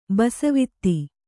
♪ basavi